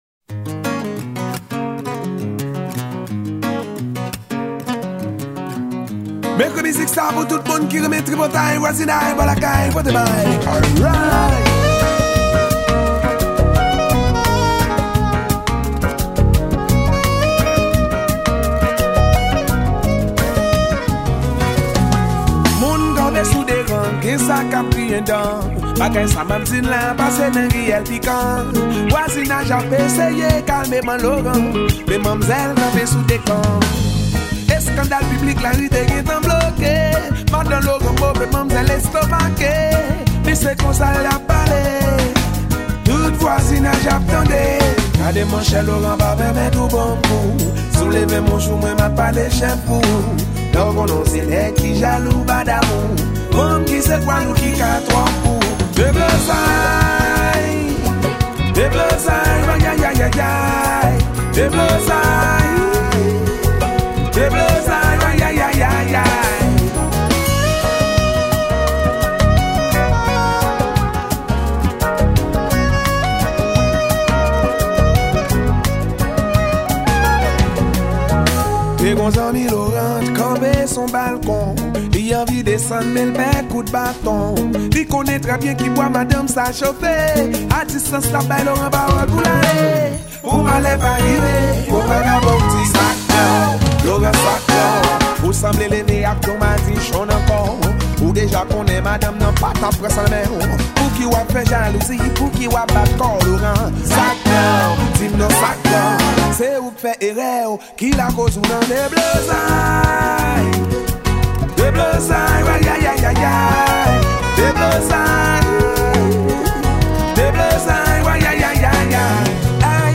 Background vocal